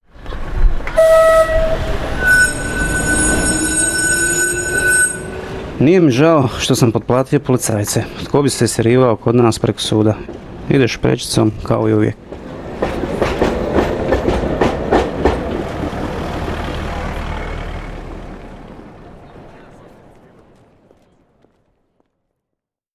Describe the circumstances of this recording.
STATEMENTS BROADCASTED ON RADIO "SLJEME":